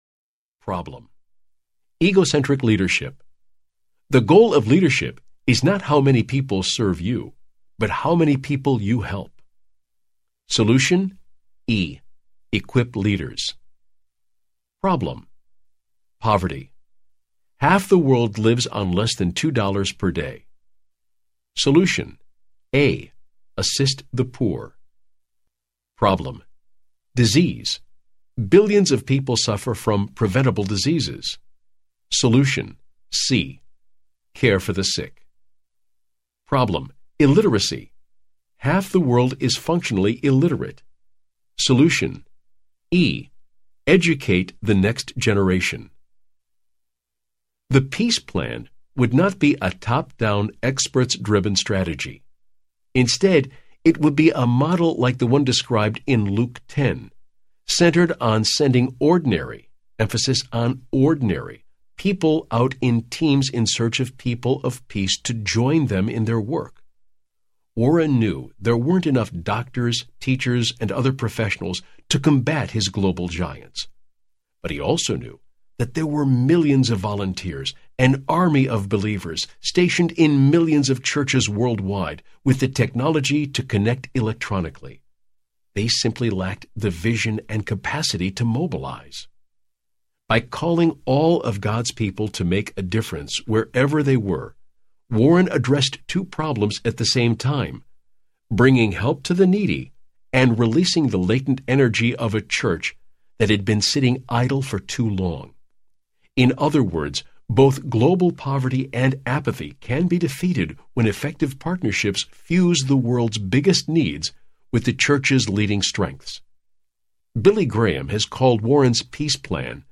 The Quiet Revolution Audiobook
Narrator